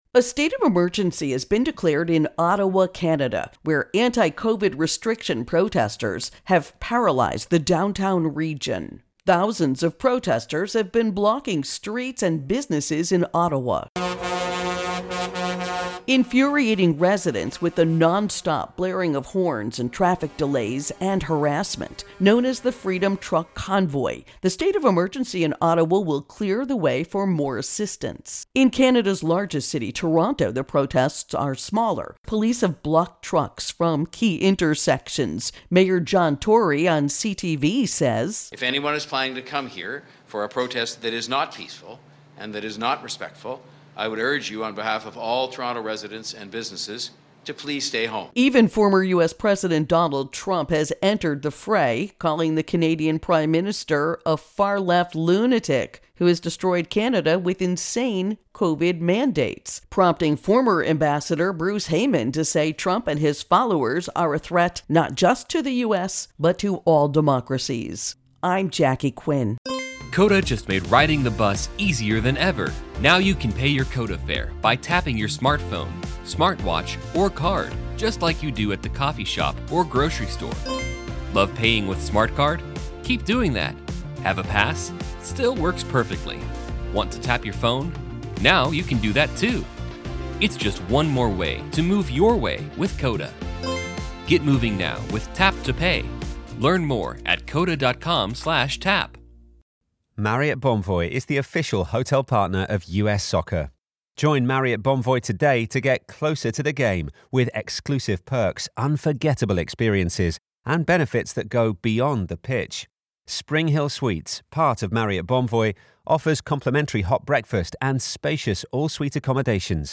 Virus Outbreak Canada Protests Intro and Voicer